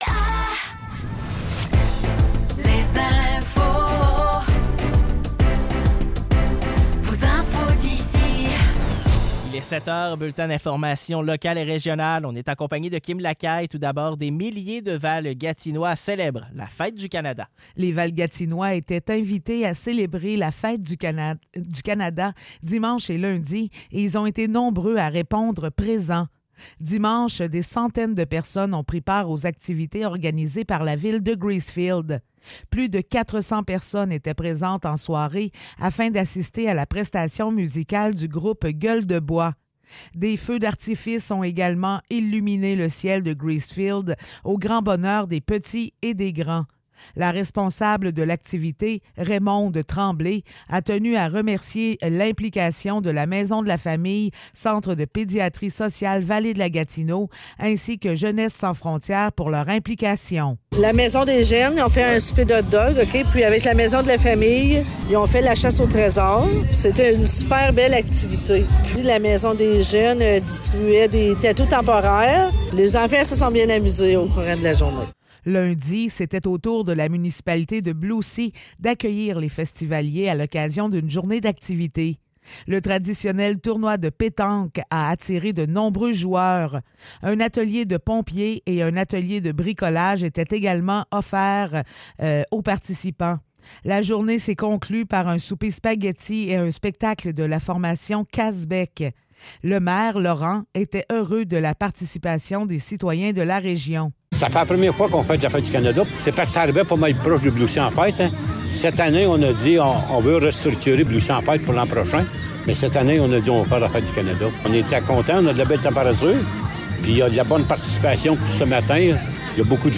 Nouvelles locales - 2 juillet 2024 - 7 h